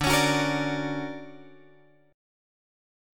D Augmented Major 7th